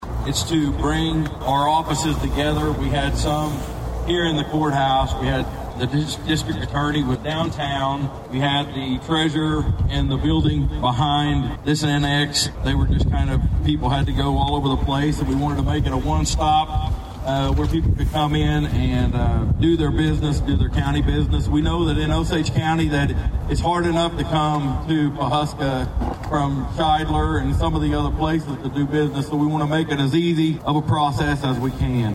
As construction continues on the Osage County Courthouse, a ribbon cutting ceremony was held on Monday signaling the unofficial opening of the three-story administration building next door.